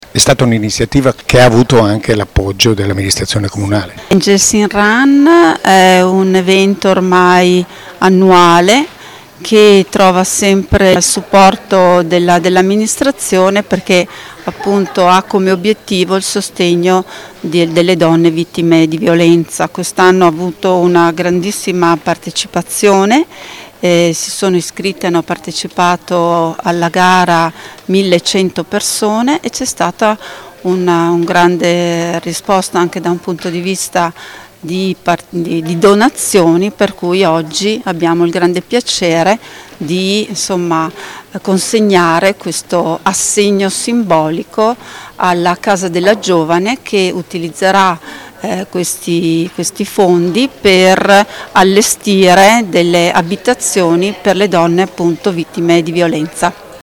Le dichiarazioni raccolte dal nostro corrispondente
Barbara Bissoli, vicesindaca e delegata alla Parità di genere
Barbara-Bissoli-Vicesindaca-e-assessora-del-comune-di-Verona-alle-Parita-di-genere-Affari-generali.mp3